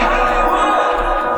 TS - CHANT (17).wav